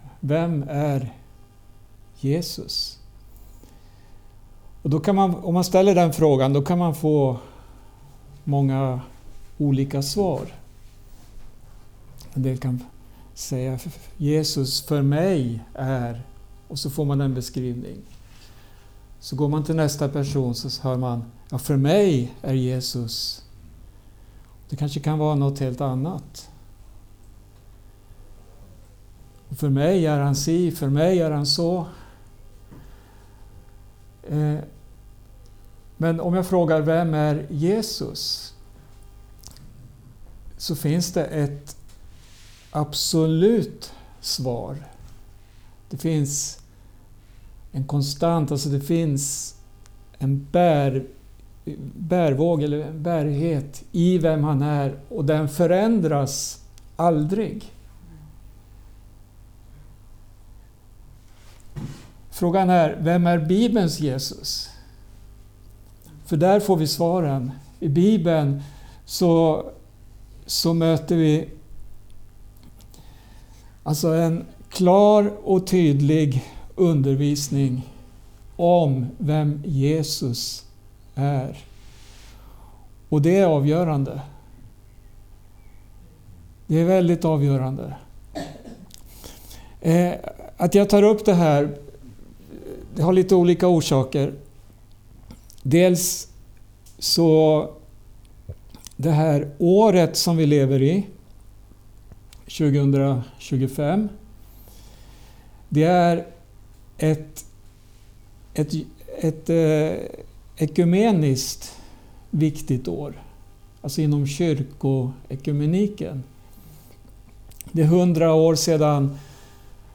Undervisning
inspelad hos församlingen i Skälby, Järfälla